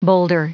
Prononciation du mot boulder en anglais (fichier audio)
Prononciation du mot : boulder